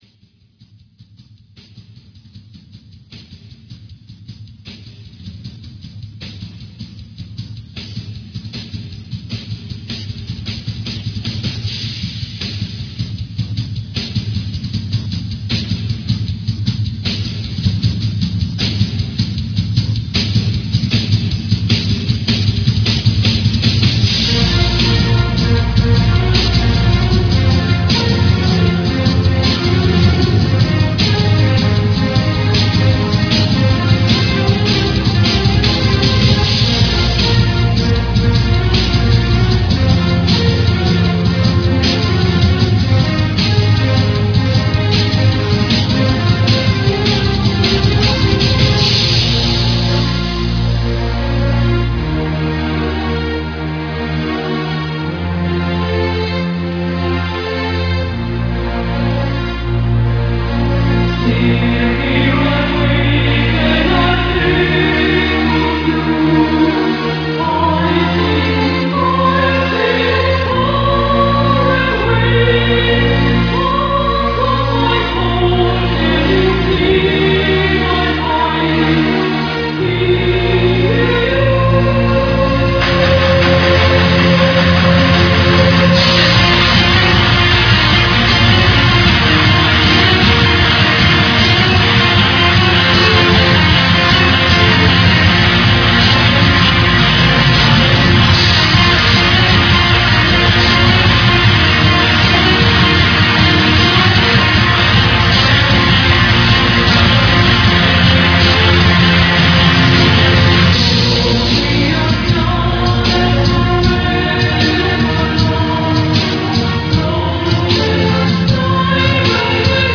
(melodic/gothic/doom metal)